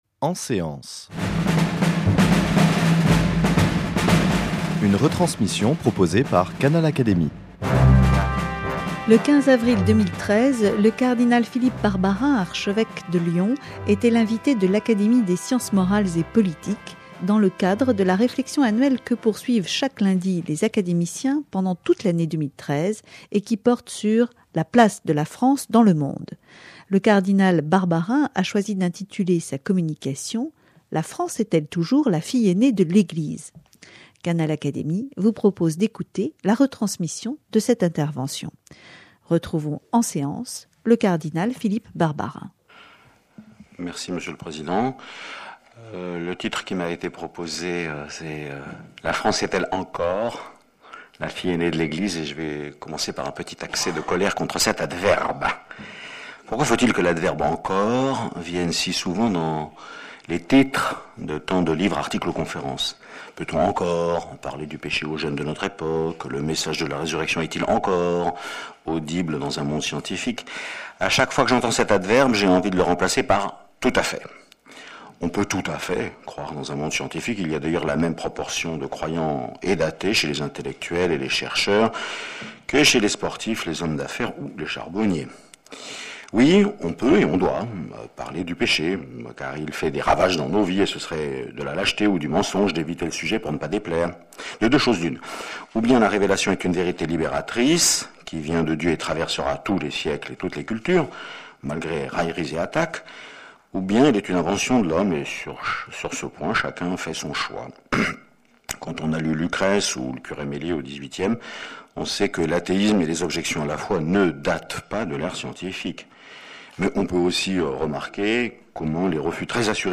Le cardinal Philippe Barbarin était l’invité de l’Académie des sciences morales et politiques, le 15 avril 2013, dans le cadre de son thème de réflexion annuel consacré, pour l’année 2013 à la place de la France dans le monde. Canal Académie vous propose d’écouter la retransmission de sa communication « La France est-elle encore la « fille aînée de l’Église » ? et l’échange des questions entre le cardinal Barbarin et les académiciens.